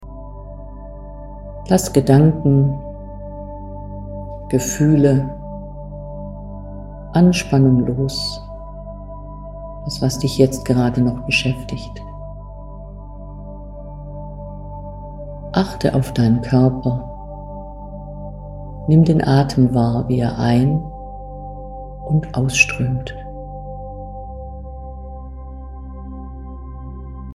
Abendreflexion Meditation